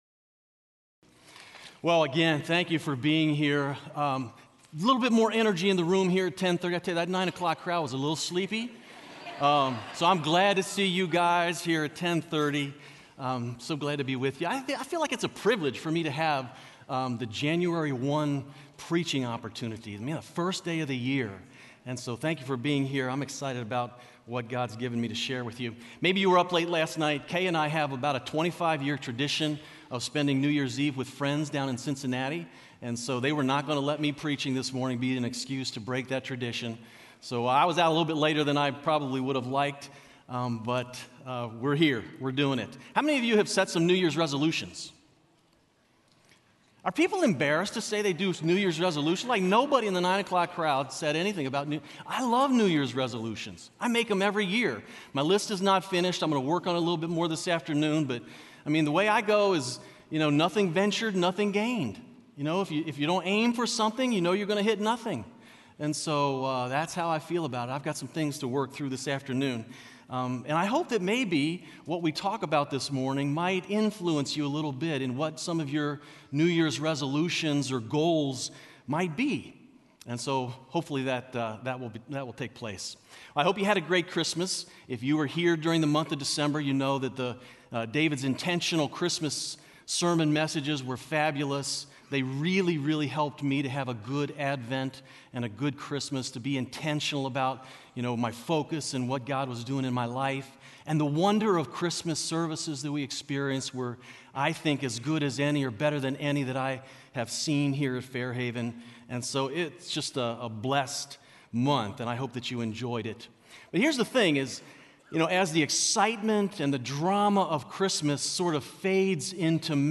Check out Living in Ordinary Time, a sermon series at Fairhaven Church.